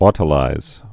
tl-īz)